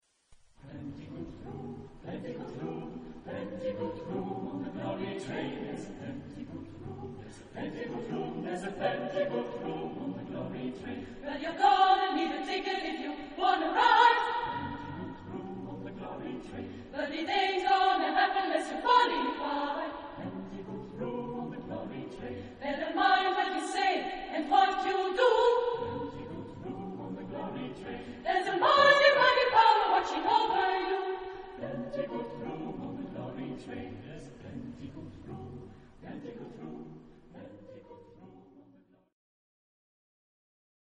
Genre-Stil-Form: geistlich ; Spiritual ; Gospel
Charakter des Stückes: energisch ; rhythmisch ; jubelnd
Chorgattung: SATB  (4 gemischter Chor Stimmen )
Tonart(en): G-Dur